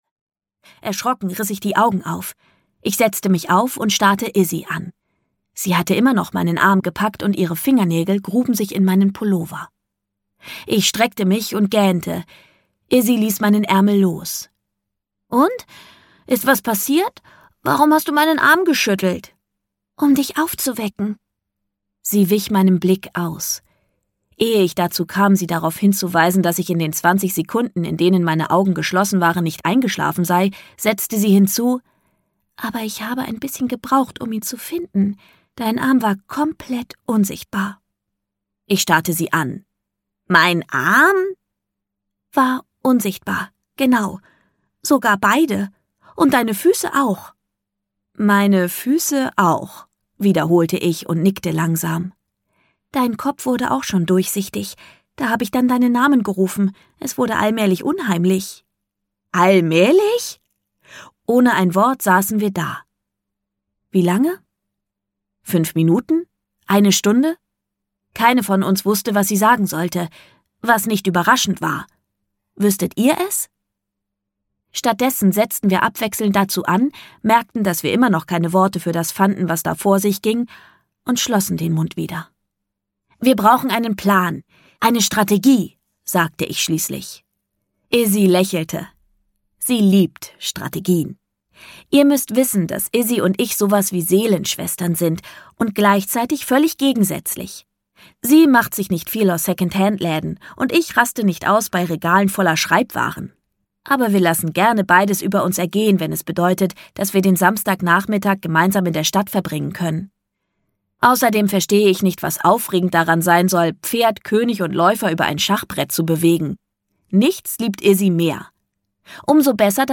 Plötzlich unsichtbar - Liz Kessler - Hörbuch